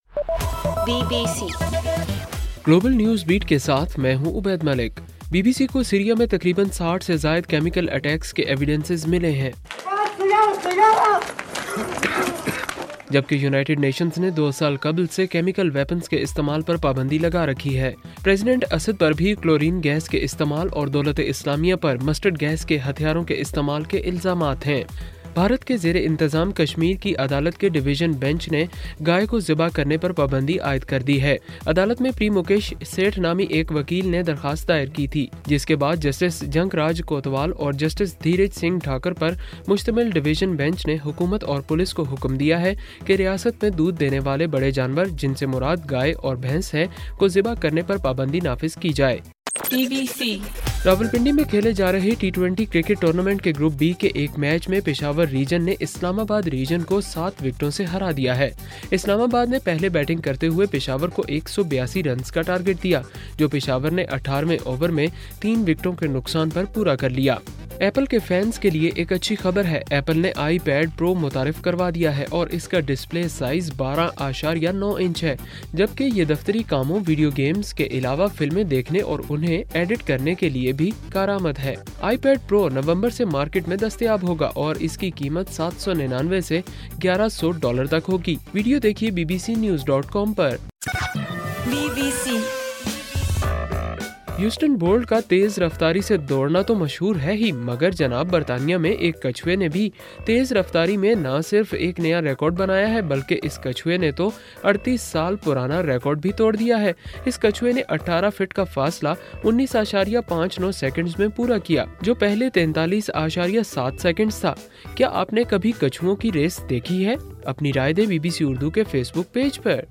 ستمبر 10: رات 12 بجے کا گلوبل نیوز بیٹ بُلیٹن